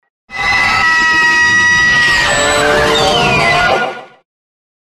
Здесь вы можете слушать и скачивать аудиофайлы, создающие атмосферу таинственности и страха. Подборка включает различные вариации звуков – от шепота до жуткого смеха.
Момо внезапно громко закричала